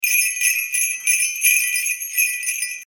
Christmas Sleigh Bells Loop Sound Effect
Description: Christmas sleigh bells loop sound effect. Santa Claus rides his reindeer sleigh across the sky, ringing festive bells. The jingling sleigh bells create a joyful and magical Christmas atmosphere.
Christmas-sleigh-bells-loop-sound-effect.mp3